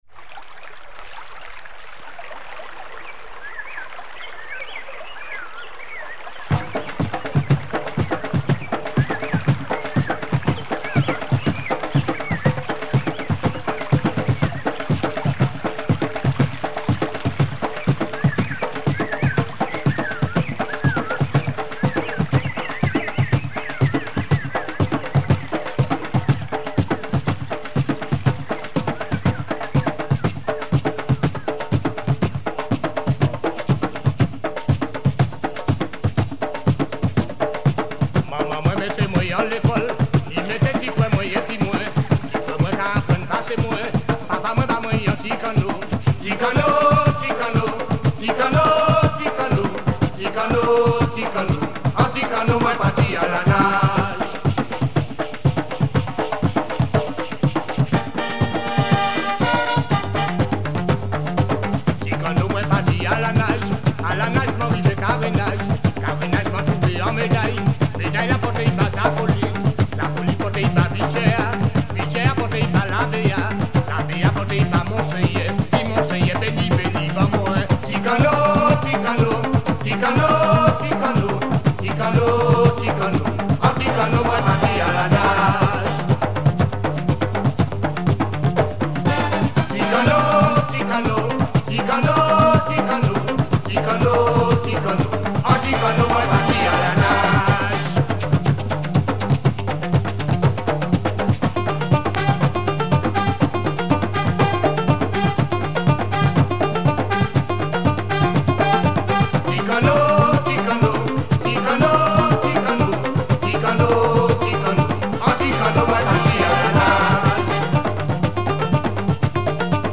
West Indian groove and gwo ka
very percussive modern / electroid